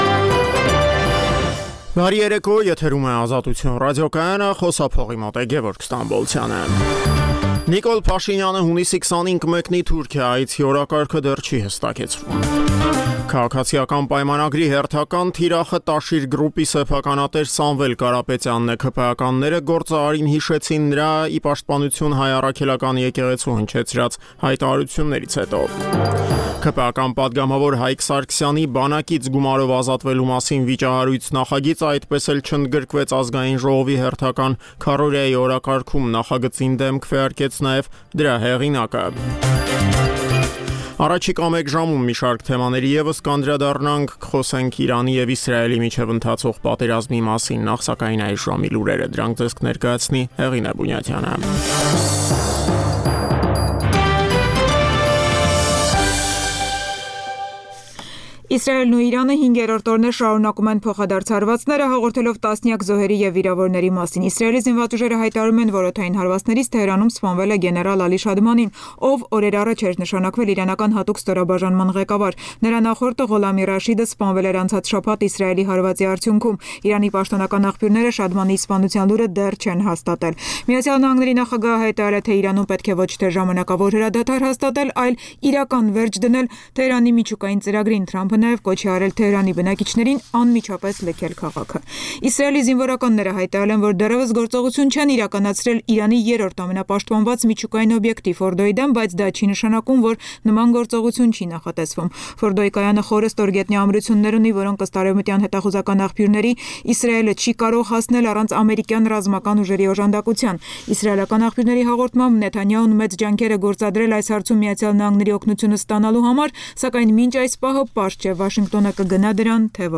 «Ազատություն» ռադիոկայանի օրվա հիմնական թողարկումը: Տեղական եւ միջազգային լուրեր, ռեպորտաժներ օրվա կարեւորագույն իրադարձությունների մասին, հարցազրույցներ, մամուլի տեսություն: